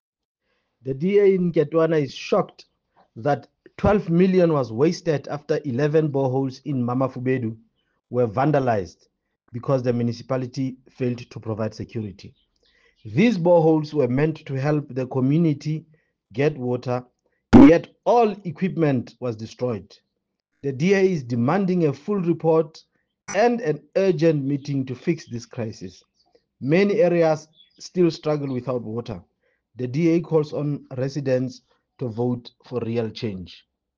Sesotho soundbites by Cllr Diphapang Mofokeng and Afrikaans soundbite by Cllr Anelia Smit.